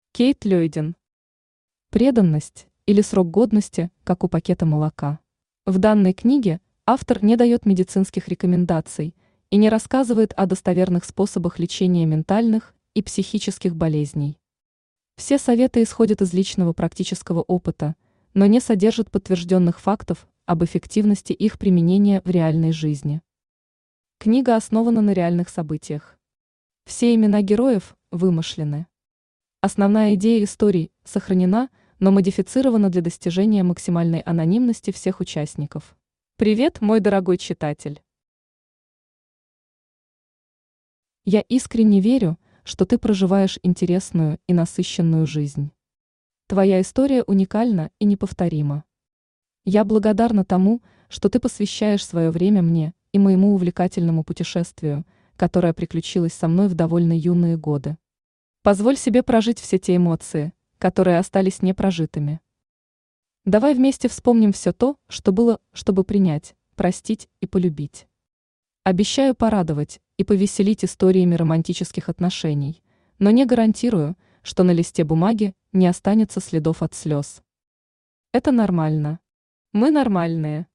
Читает: Авточтец ЛитРес
Аудиокнига «Преданность, или срок годности как у пакета молока».